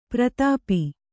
♪ pratāpi